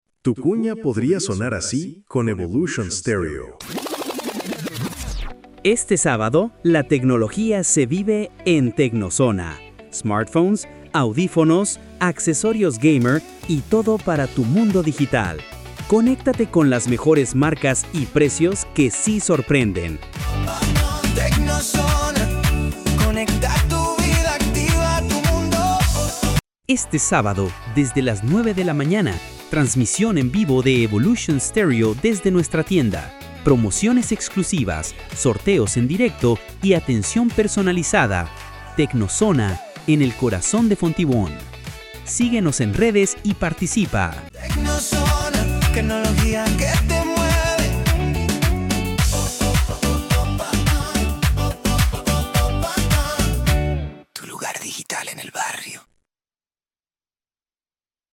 Ejemplo de cuña comercial Paquete Premium Demostración ilustrativa del formato y calidad sonora.